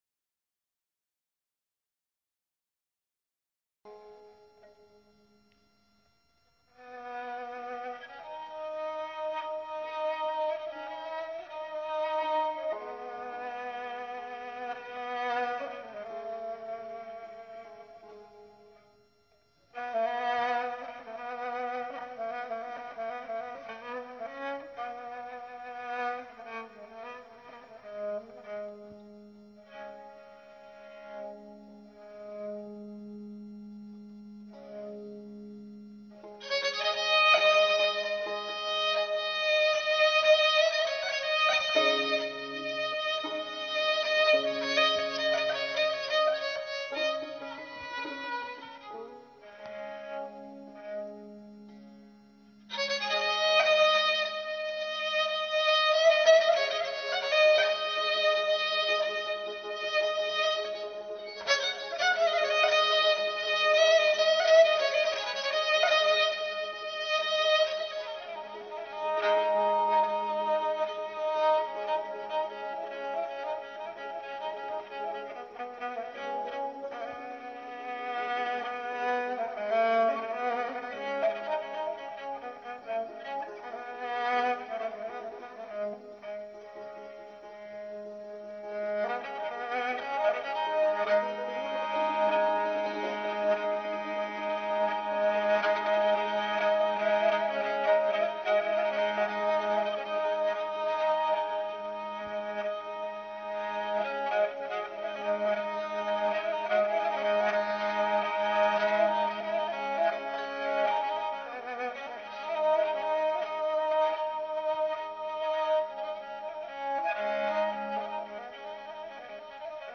با صدای ملکوتی